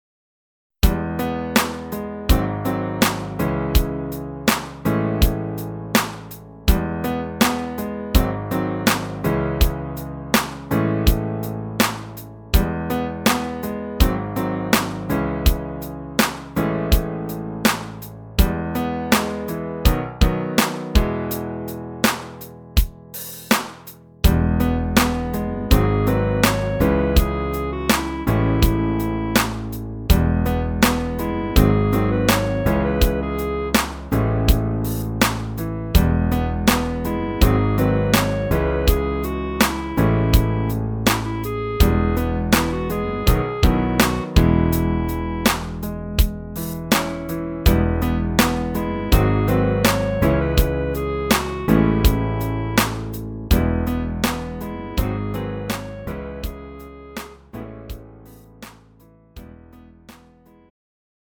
음정 -1키
장르 pop 구분 Lite MR